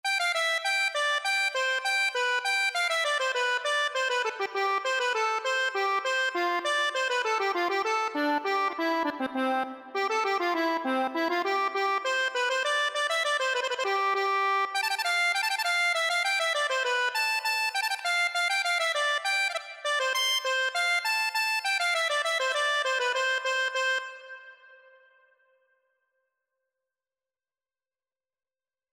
Traditional Turlough O Carolan Miss Murphy Accordion version
C major (Sounding Pitch) (View more C major Music for Accordion )
2/2 (View more 2/2 Music)
Traditional (View more Traditional Accordion Music)